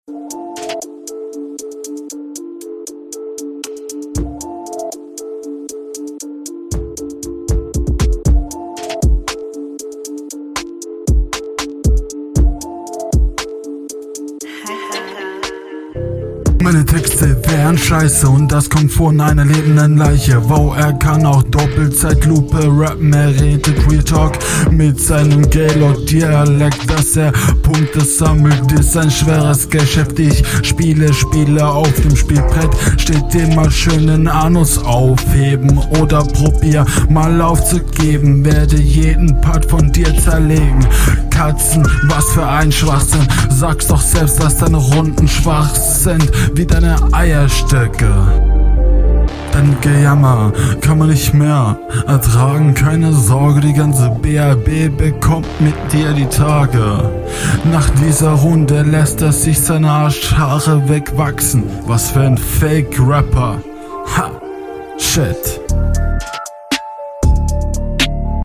Stimmlich ist das wieder genauso wie in den letzten 2 Runden.